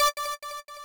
lead2_d5s.ogg